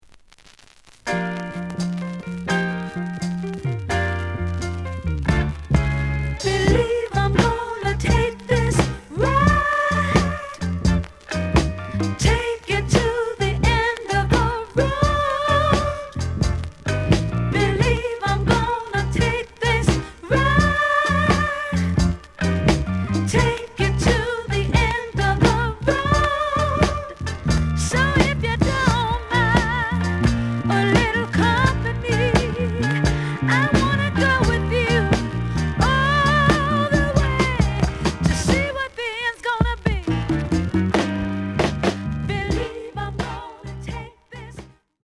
The audio sample is recorded from the actual item.
●Genre: Soul, 70's Soul
Jukebox marks.